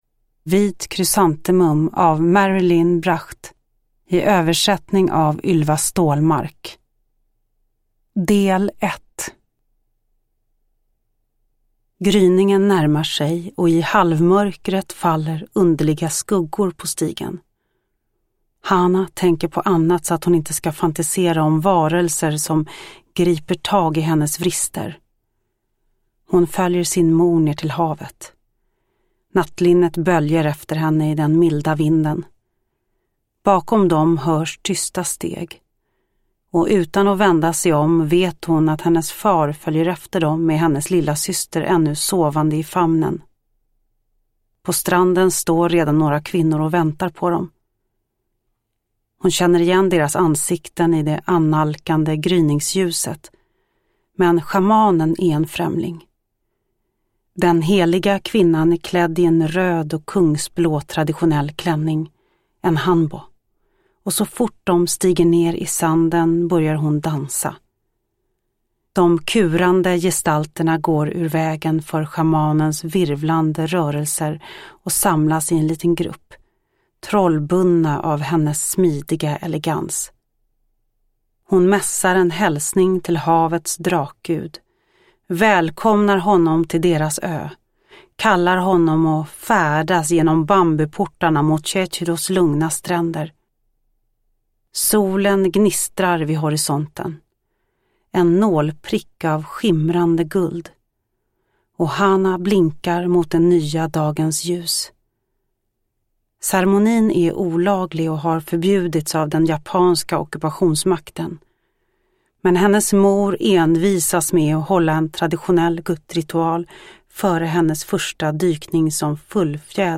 Vit Krysantemum, del 1 av 2 – Ljudbok
Uppläsare: Lo Kauppi